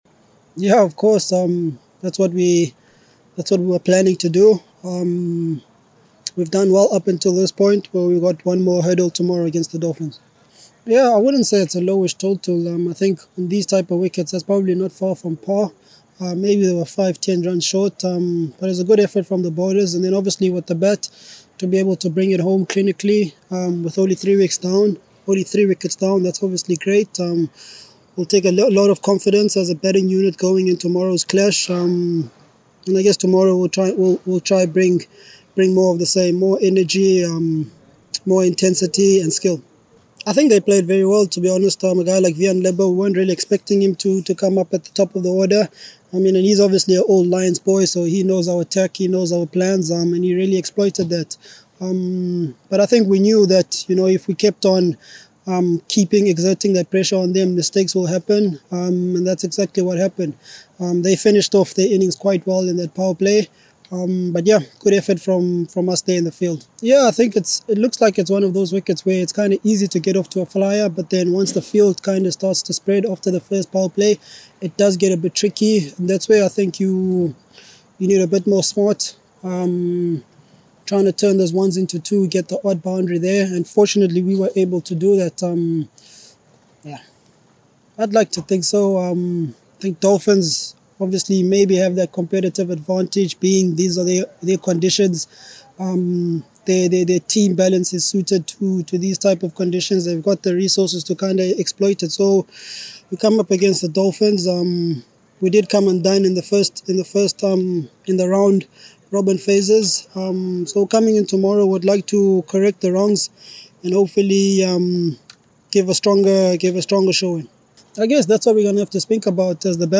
Lions captain Temba Bavuma speaks about reaching CSA T20 Challenge final against Dolphins